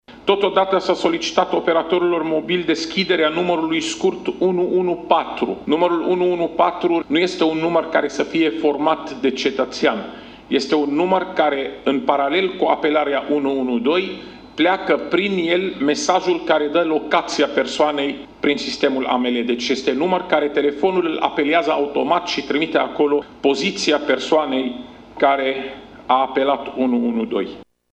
Şeful Departamentului pentru Situaţii de Urgenţă, Raed Arafat, a prezentat, în această seară, un ‘raport de etapă’ ce prevede 14 măsuri prin care se doreşte reorganizarea sistemului de urgenţă 112.